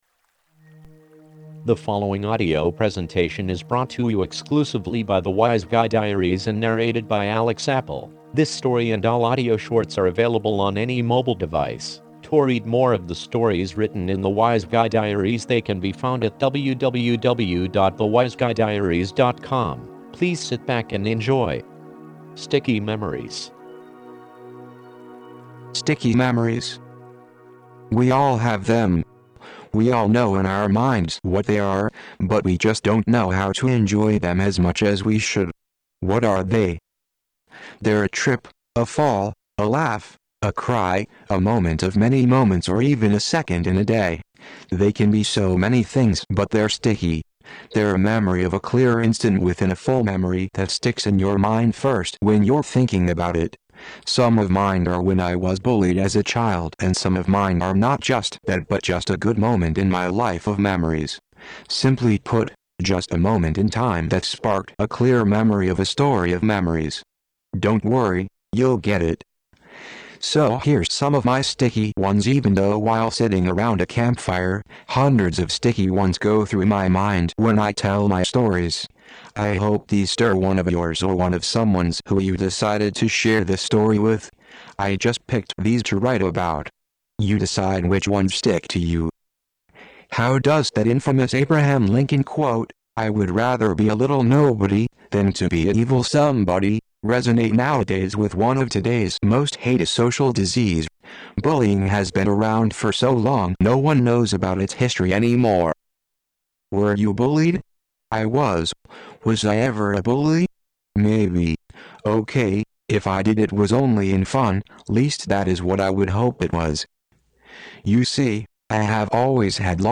To LISTEN to the audio short story if you’re like me and fall asleep in a book, press PLAY>